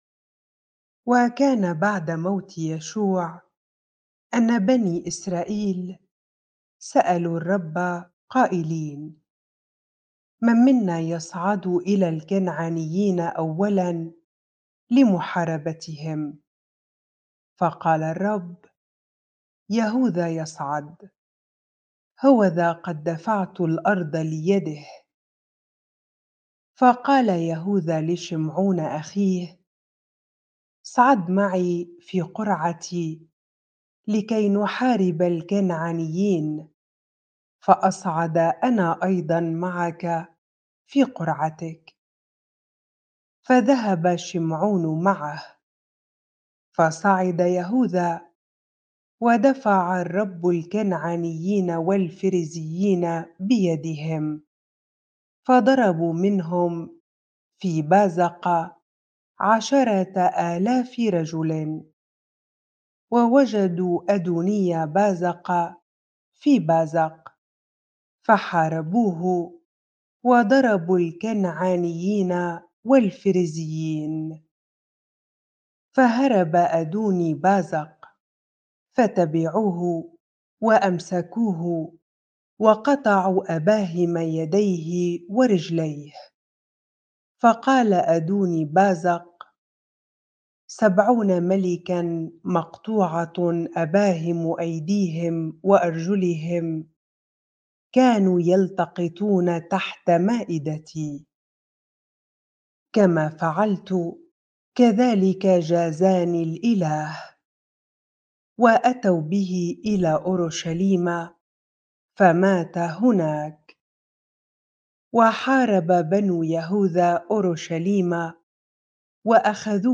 bible-reading-Judges 1 ar